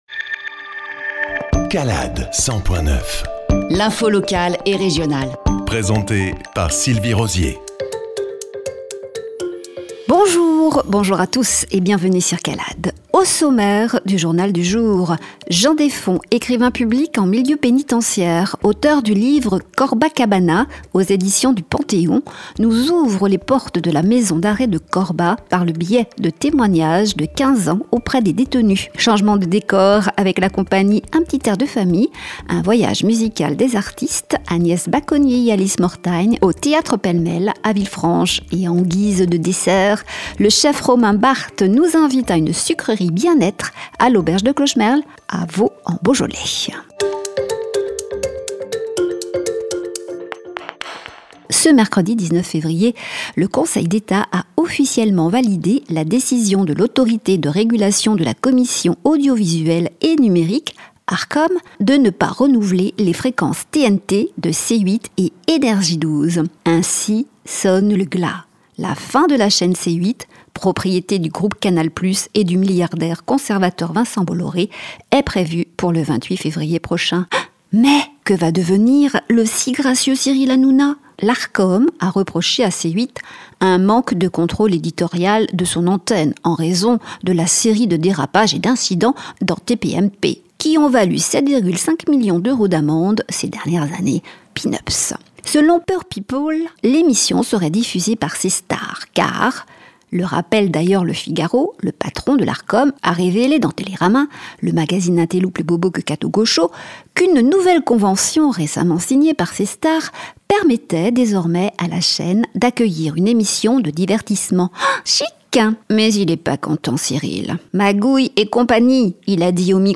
Journal du 20-02-25